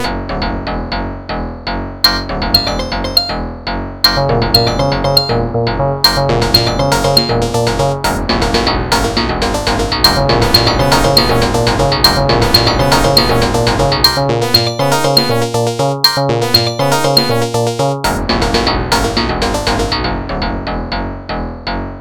Short chaotic retro melody